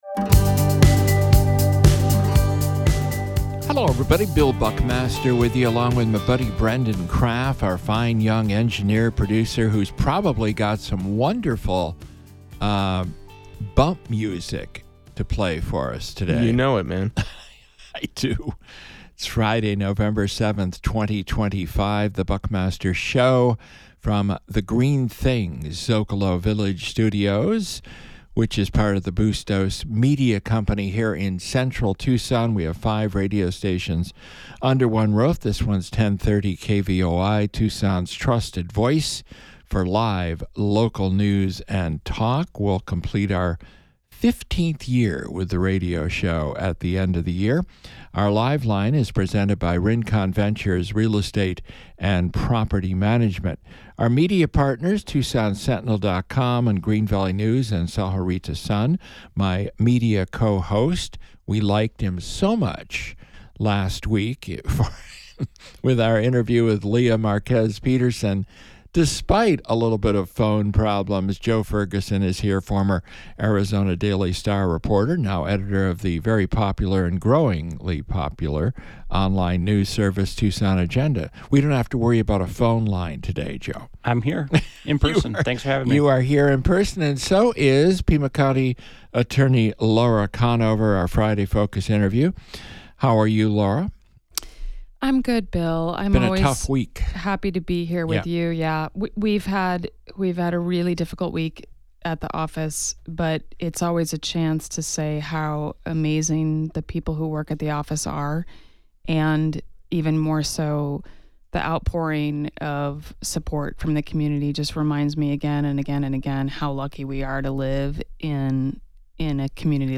A Conversation with Pima County Attorney Laura Conover